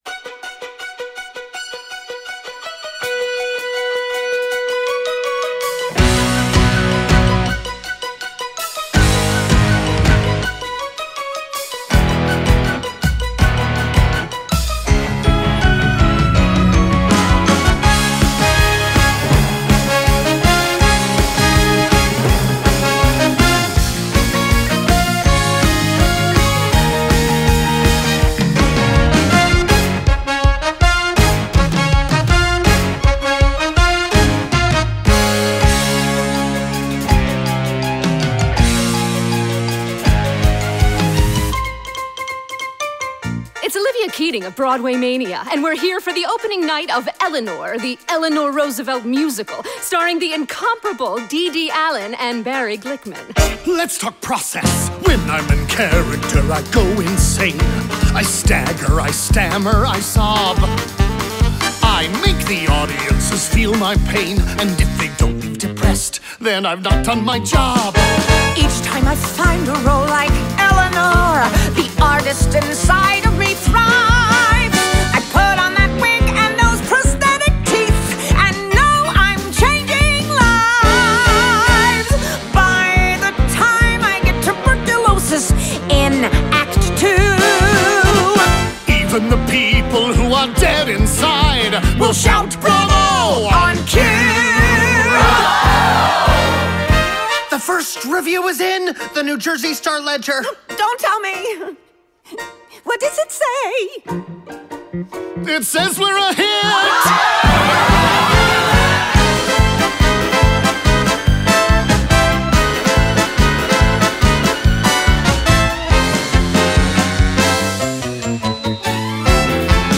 Soundtrack, Musical Theatre